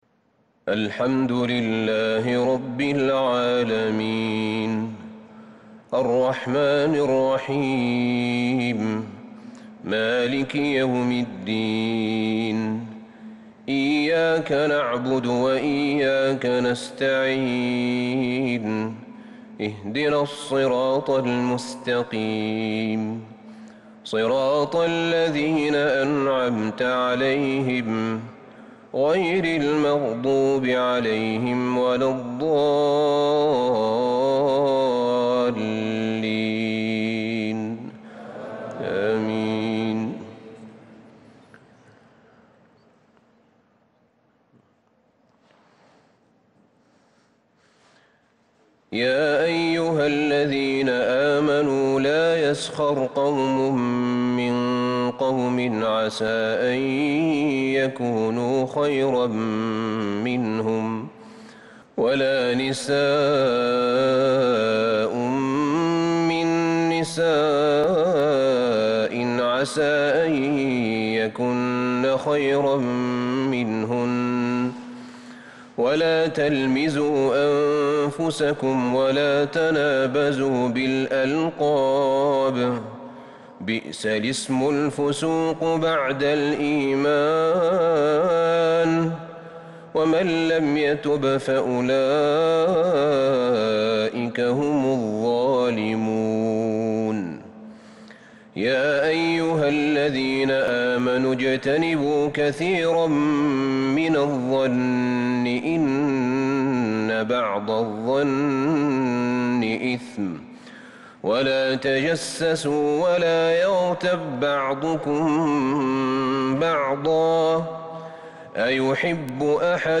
صلاة التهجد | ليلة 27 رمضان 1442 (الحجرات 11-الذاريات 46 ) | tahajud prayer The 27rd night Ramadan 1442H | > تراويح الحرم النبوي عام 1442 🕌 > التراويح - تلاوات الحرمين